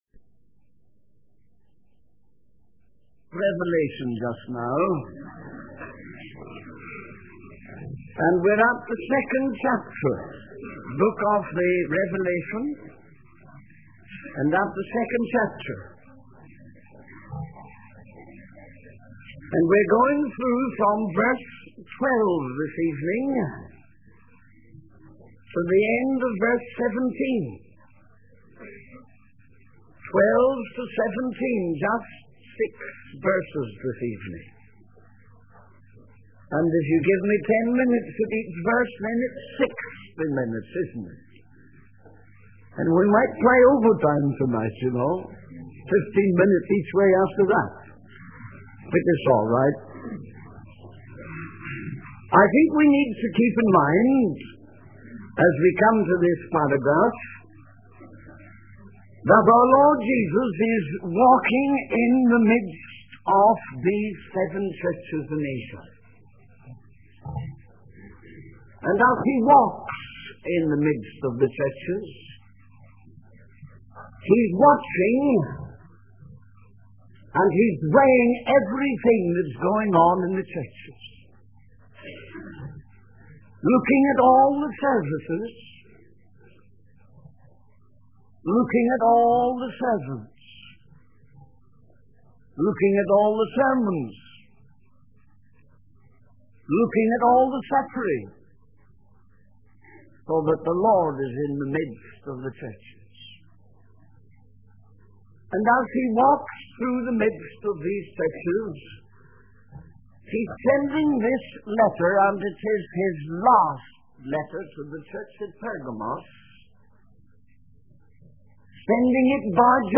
In this sermon, the preacher emphasizes the importance of not being a worldly Christian and warns that aligning oneself with the world makes one an enemy of God. The sermon is based on the book of Revelation, specifically chapter 2, where Jesus addresses the seven churches.
The preacher encourages the congregation to keep their focus on Jesus and to overcome the challenges they face, promising that those who overcome will receive blessings such as eating from the hidden manna and receiving a white stone with a new name written on it.